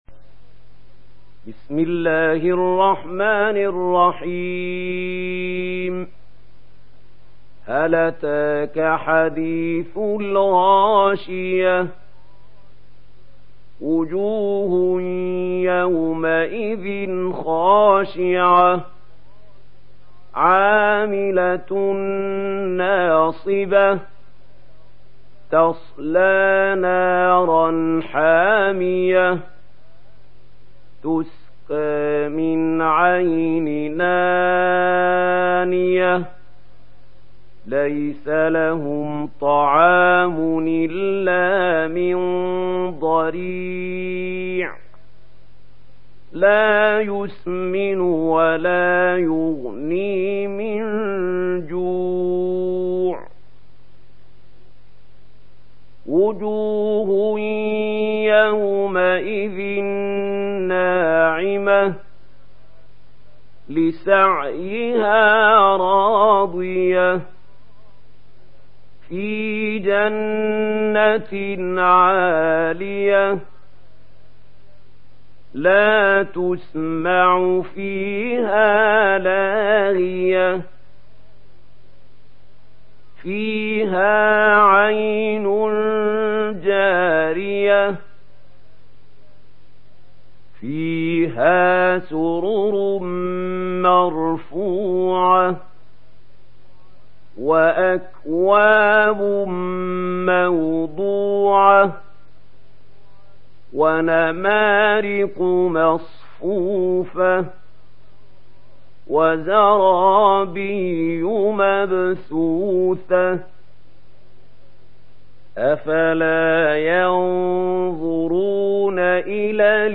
دانلود سوره الغاشيه mp3 محمود خليل الحصري روایت ورش از نافع, قرآن را دانلود کنید و گوش کن mp3 ، لینک مستقیم کامل